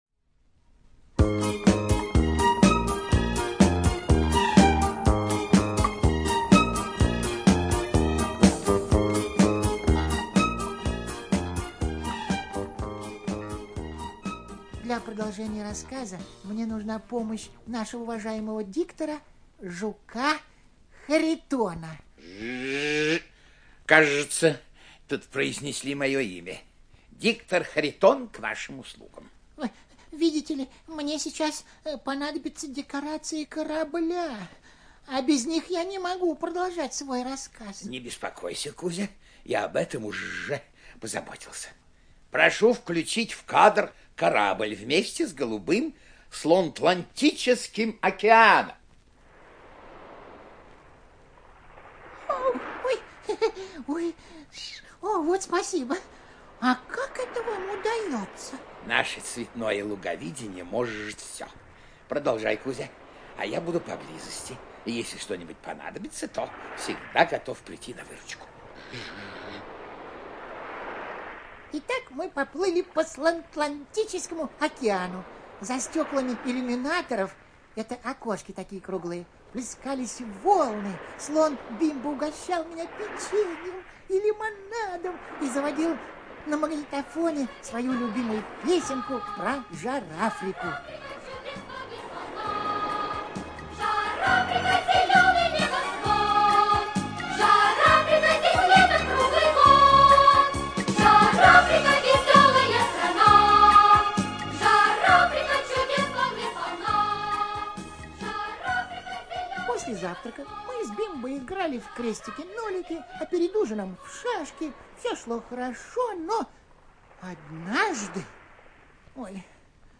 ЖанрДетский радиоспектакль